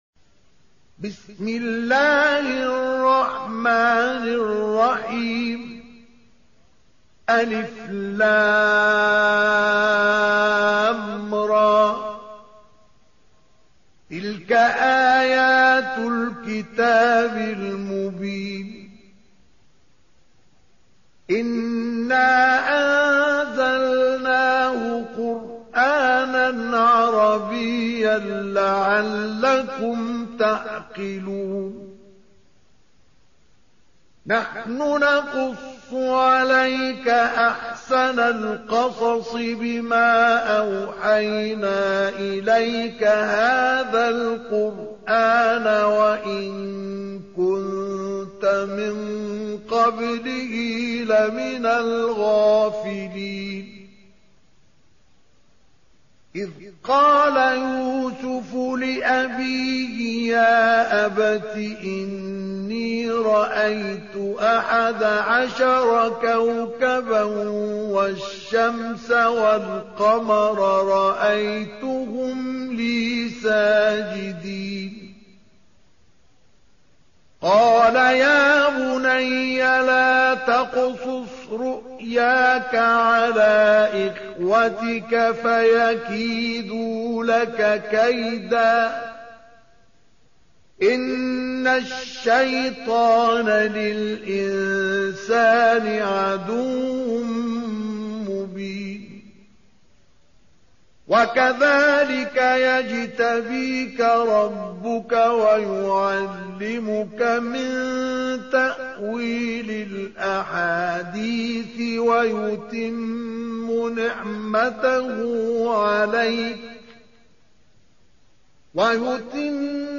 Surah Sequence تتابع السورة Download Surah حمّل السورة Reciting Murattalah Audio for 12. Surah Y�suf سورة يوسف N.B *Surah Includes Al-Basmalah Reciters Sequents تتابع التلاوات Reciters Repeats تكرار التلاوات